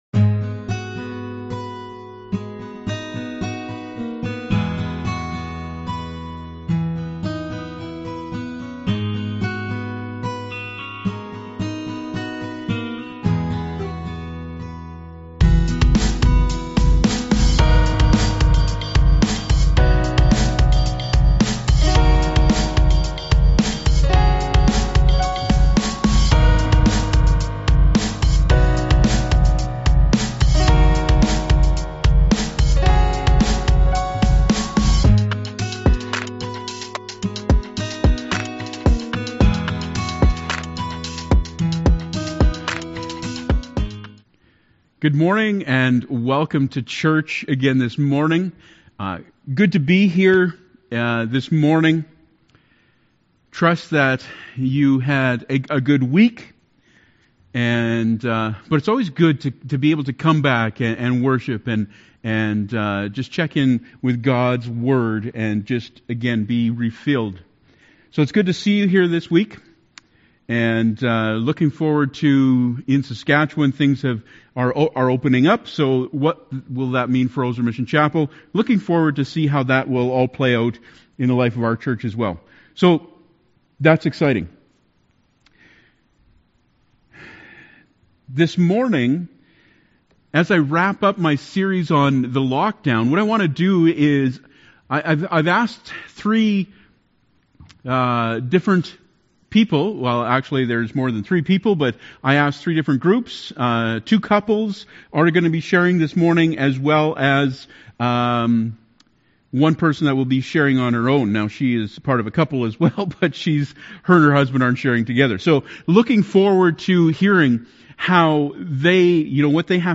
June 14th Service